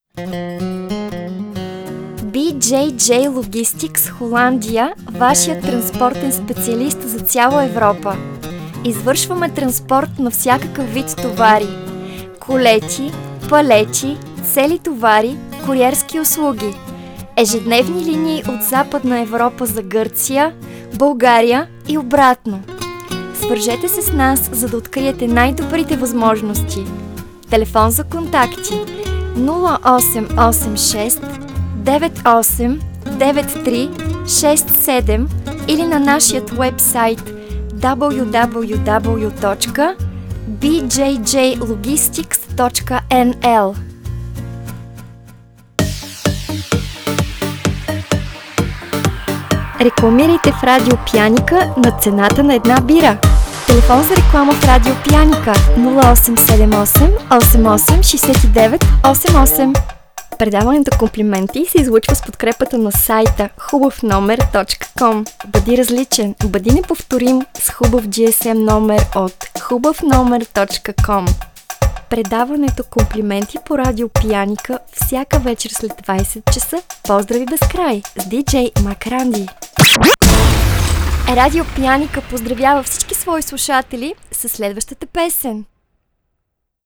Radio Commercial
Deze is op verschillende kanalen in Bulgarije te horen.